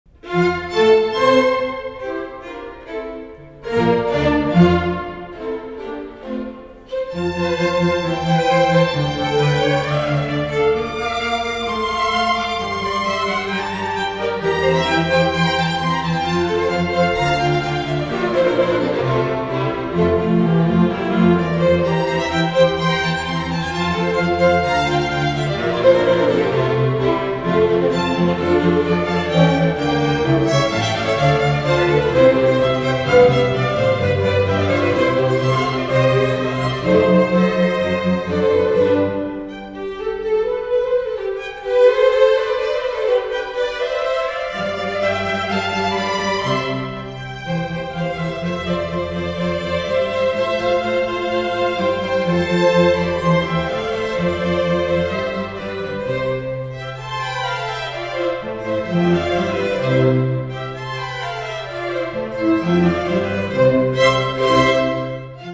St. Paul's School String Ensemble
Chapel
violins:
viola:
violoncelli:
contrabass: